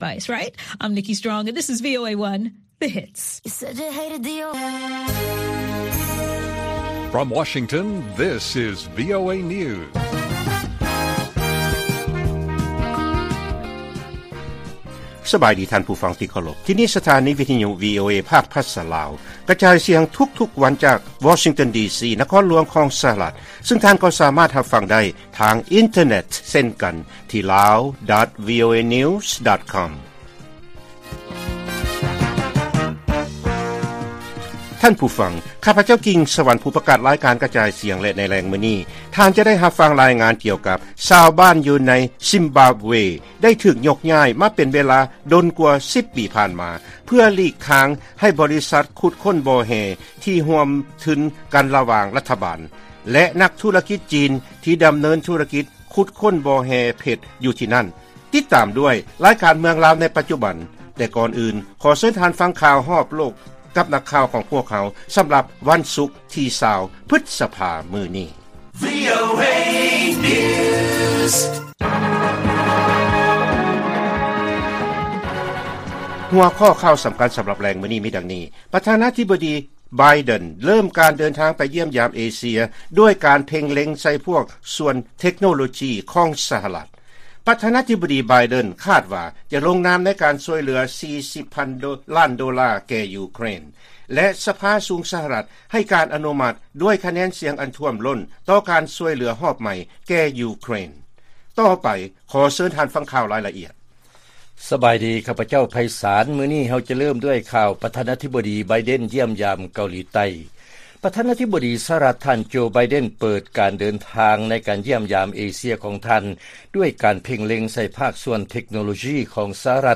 ລາຍການກະຈາຍສຽງຂອງວີໂອເອ ລາວ: ປະທານາທິບໍດີໄບເດັນ ເລີ້ມການເດີນທາງຢ້ຽມຢາມ ເອເຊຍ ດ້ວຍການເພັ່ງເລັງໃສ່ພາກສ່ວນເທັກໂນໂລຈີຂອງ ສະຫະລັດ